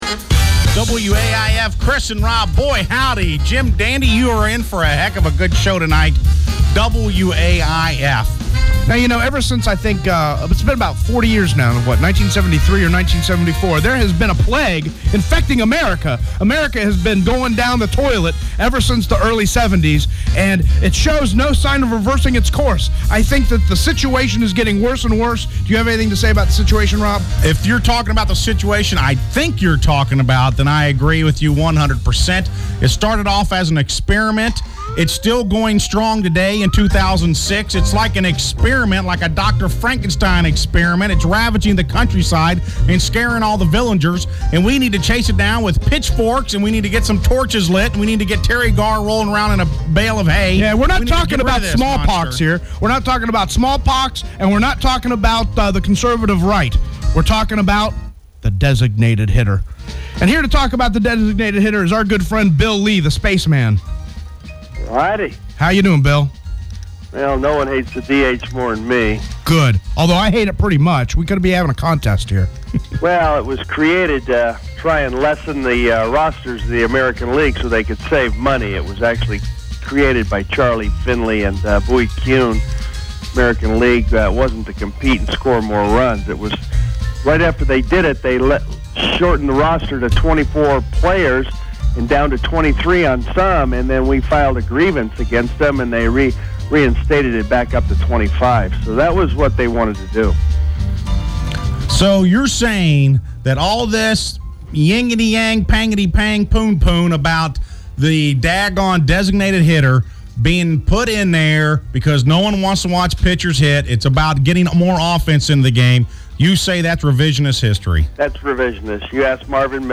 Whether you're a Red Sox fan, or a fan of baseball or not, these interviews with one of the wittiest players to come out of the Major Leagues are fun and upbeat.
Bill "The Spaceman" Lee Interview April 4, 2006